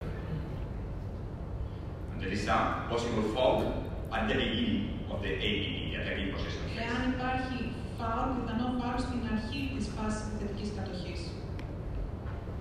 Όπως ακούγεται ξεκάθαρα να λέει στο ηχητικό, και παρά την… λειψή μετάφραση που υπήρξε, αναφέρει χαρακτηριστικά: «There is some possible foul», που σημαίνει ότι «υπάρχει πιθανό φάουλ».
Ακούστε τα δύο ηχητικά του Ισπανού, όπου αναφέρει ξεκάθαρα για την παράβαση του μέσου της ΑΕΚ: